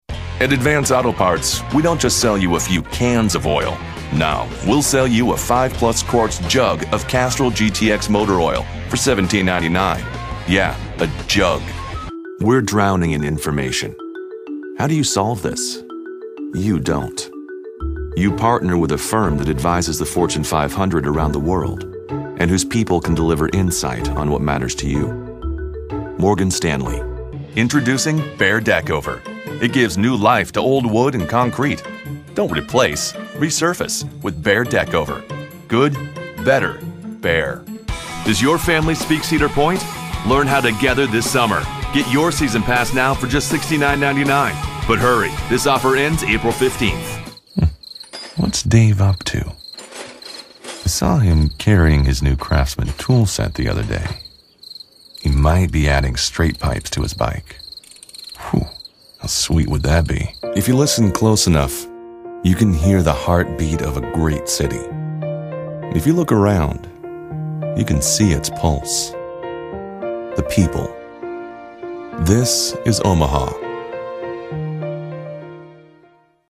Persuasive, Positive, Real.
Commercial